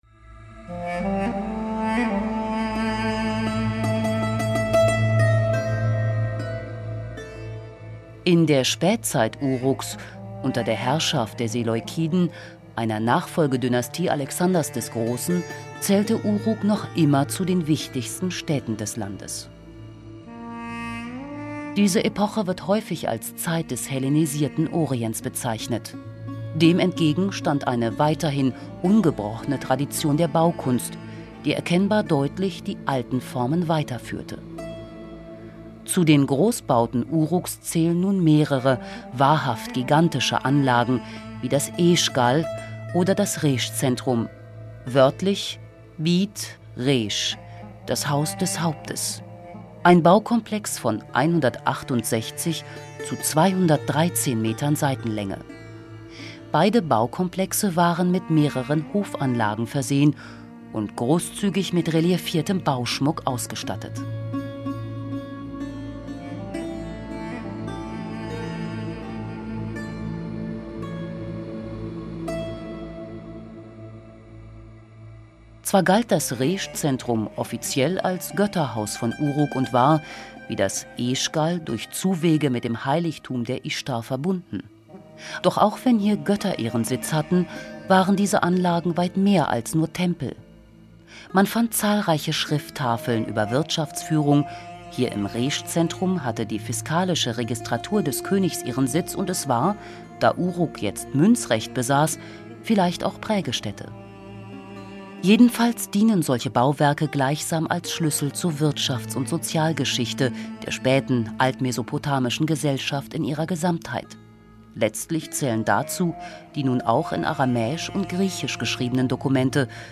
These audioguides are packed with insightful background information on the Epic of Gilgamesh and the ancient megacity Uruk.